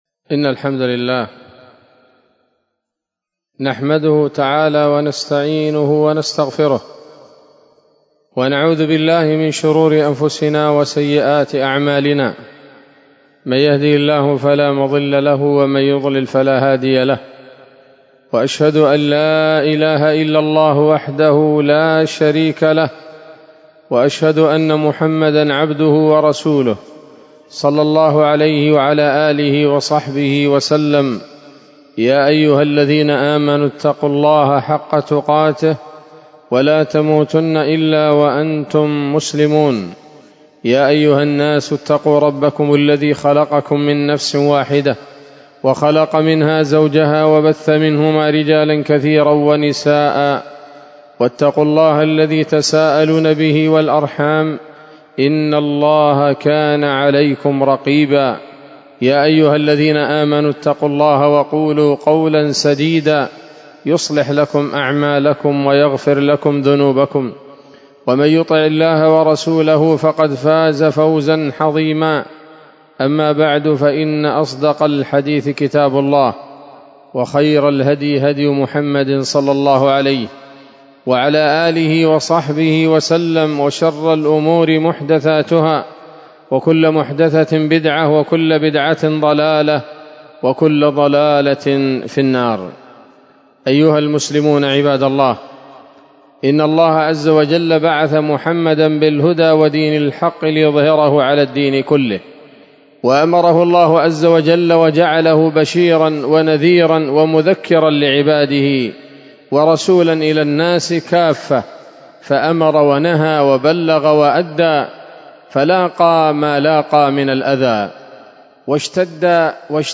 خطبة جمعة بعنوان: (( الإسراء والمعراج بين أهل الحق وأهل الباطل )) 24 رجب 1446 هـ، دار الحديث السلفية بصلاح الدين